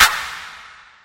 Clap 5.wav